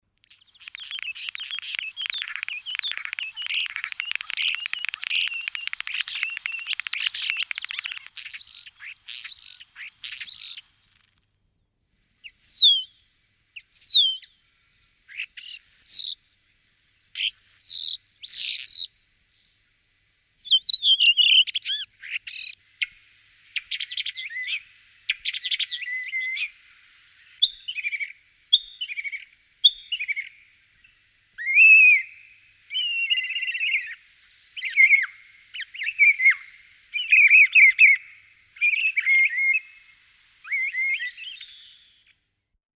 На этой странице собраны разнообразные звуки скворцов: от мелодичного пения до характерного свиста.
Голос и звуки скворца